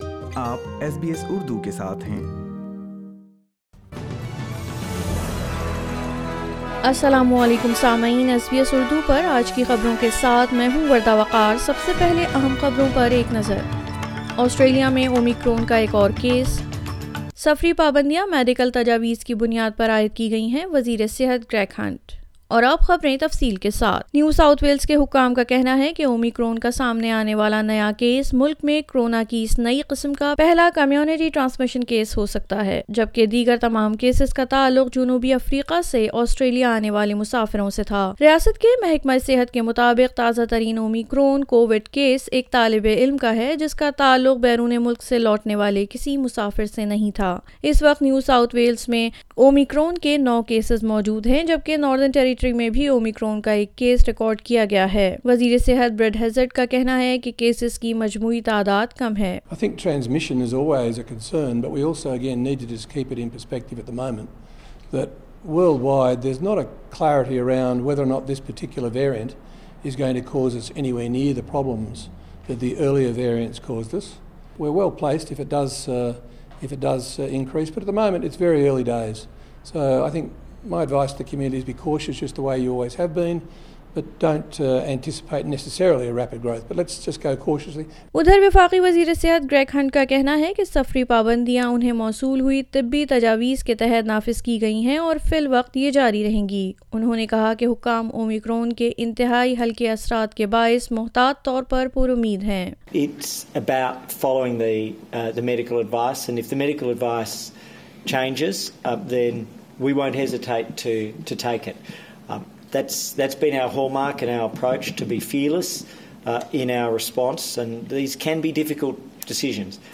SBS Urdu News 03 December 2021